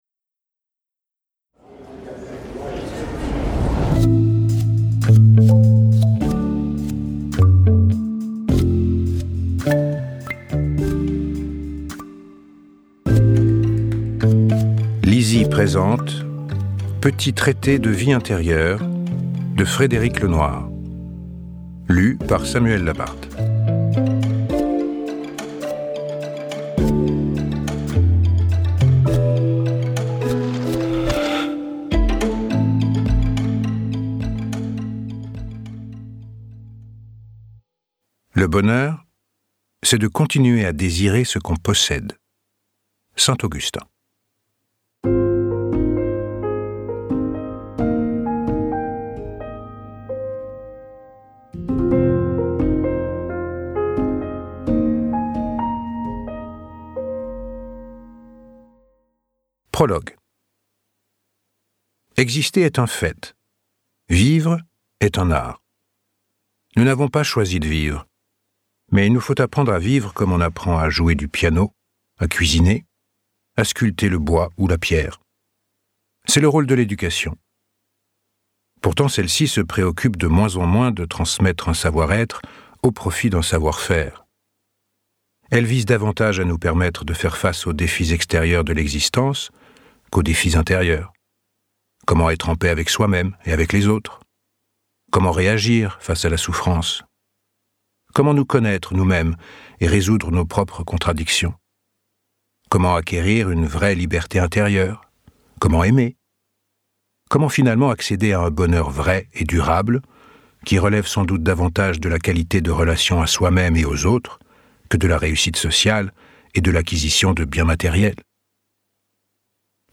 je découvre un extrait - Petit traité de vie intérieure de Frédéric.. Lenoir